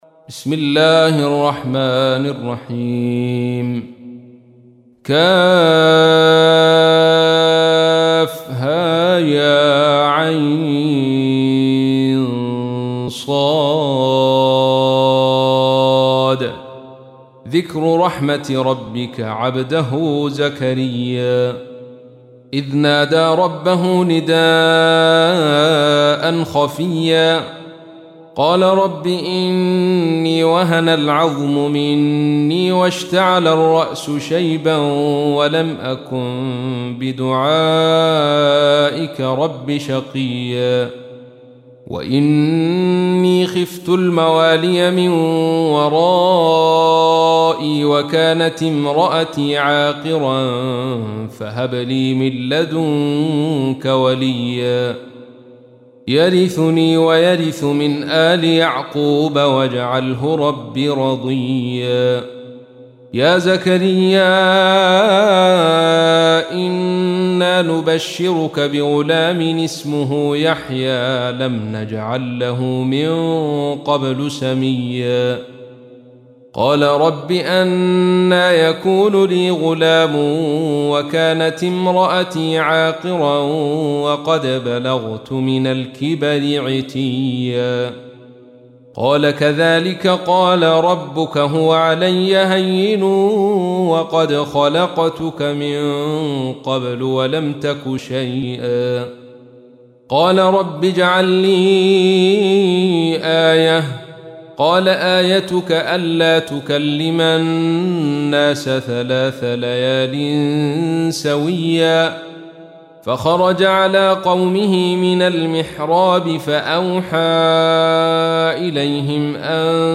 تحميل : 19. سورة مريم / القارئ عبد الرشيد صوفي / القرآن الكريم / موقع يا حسين